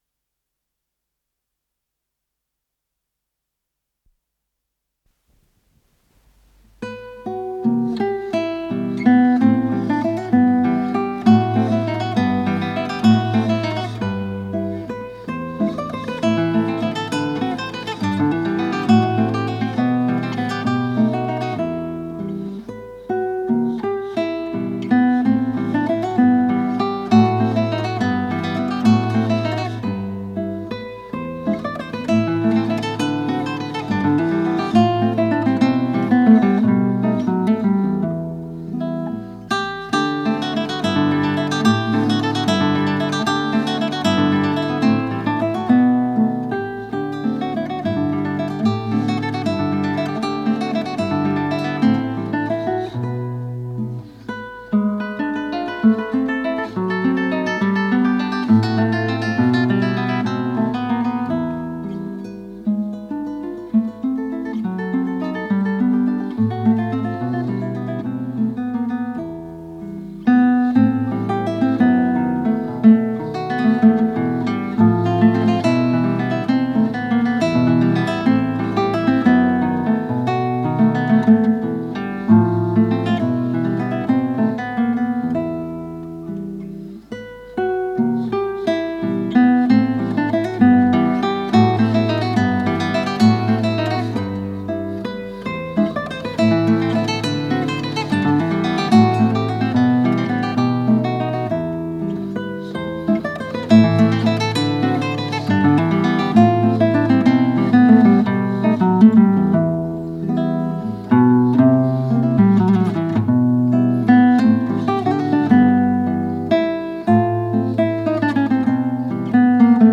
с профессиональной магнитной ленты
шестиструнная гитара
ВариантДубль моно